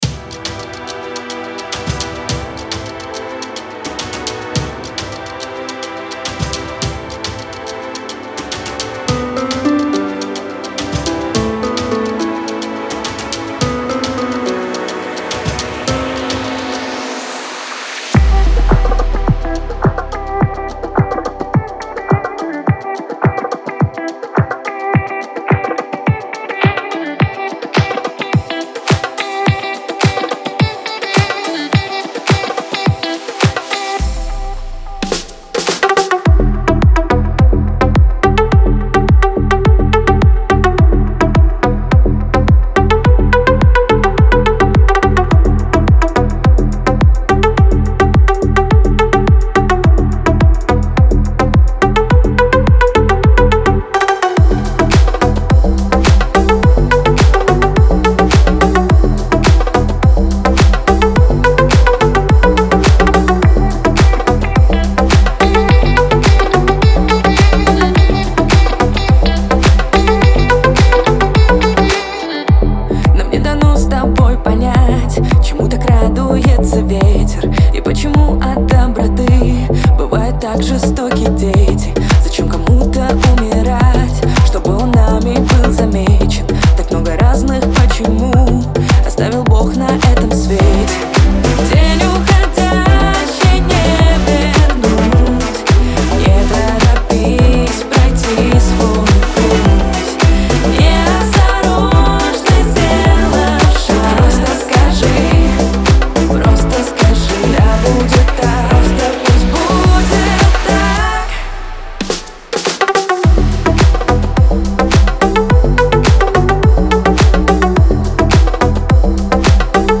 И напоследок, две перепевки.